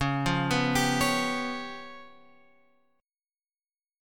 C#7b5 chord {9 8 9 10 8 x} chord